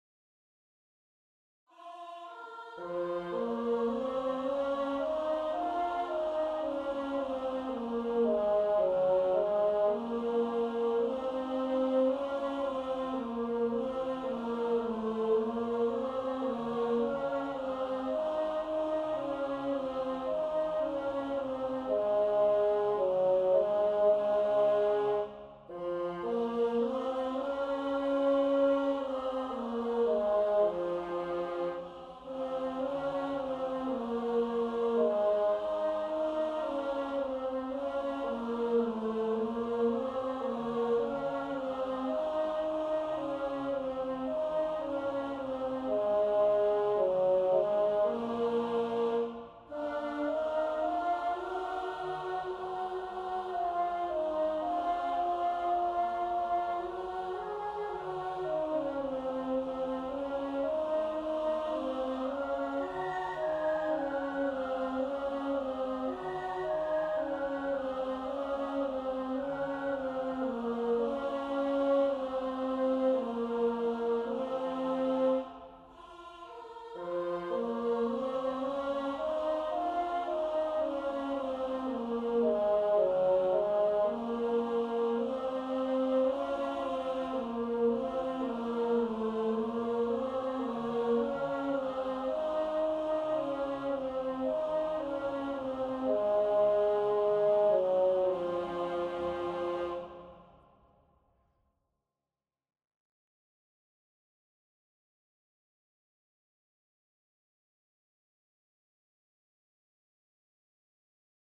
GAUDETE PRACTICE TRACKS:
4020-tenor.mp3